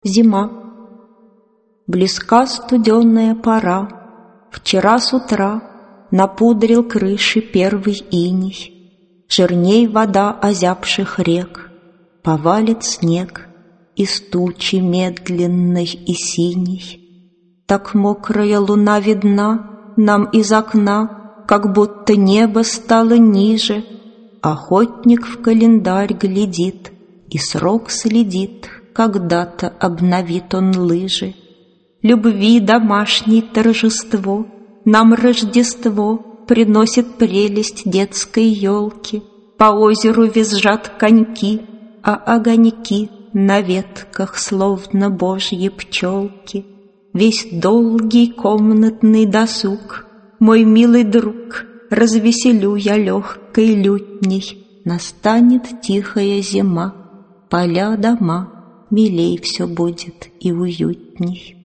Аудиокнига Стихи.
Читает Вера Павлова Автор Михаил Кузмин Читает аудиокнигу Вера Павлова.